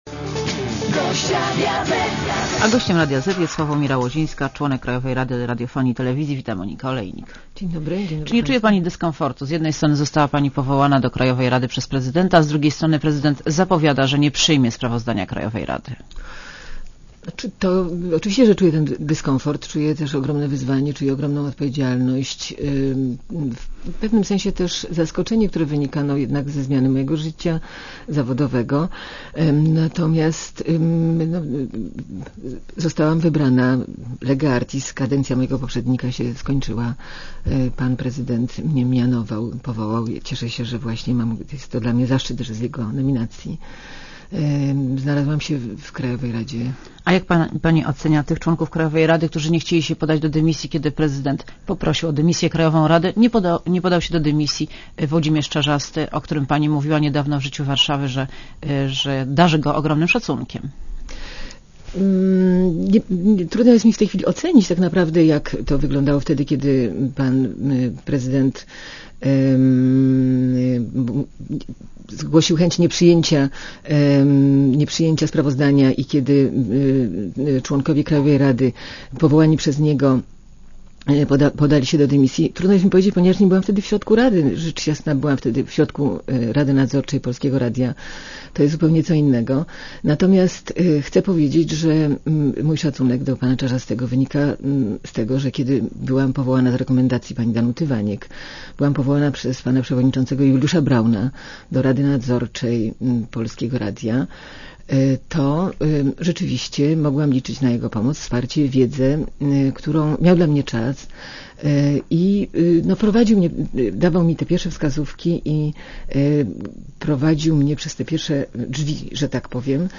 © (RadioZet) Posłuchaj wywiadu (2,8 MB) Czy nie czuje pani dyskomfortu?